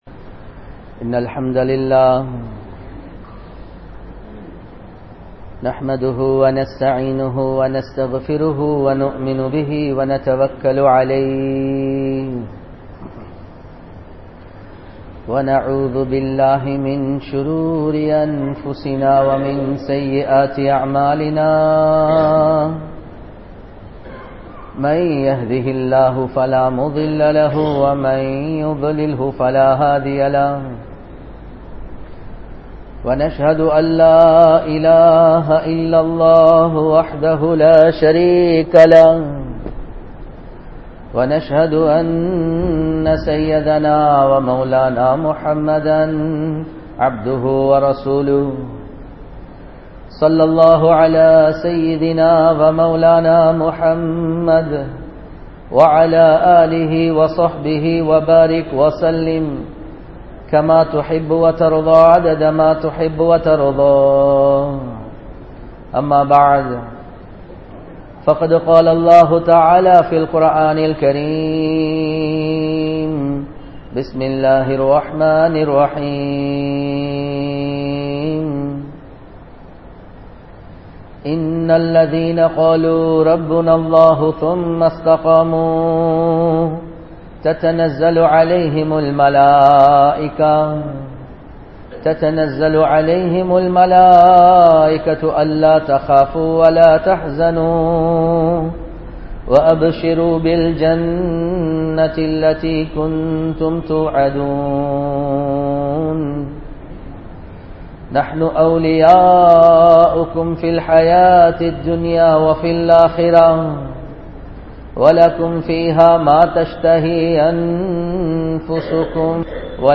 Nabi(SAW)Avarhalin Vali Muraihal (நபி(ஸல்)அவர்களின் வழி முறைகள்) | Audio Bayans | All Ceylon Muslim Youth Community | Addalaichenai